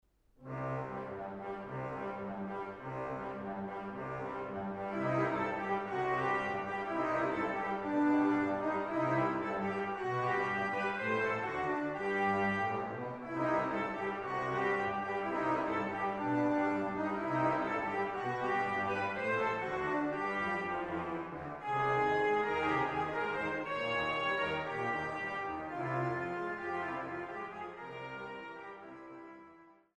Oberlinger Orgel der Stadtkirche Dillenburg
Acht Charakterstücke für Orgel solo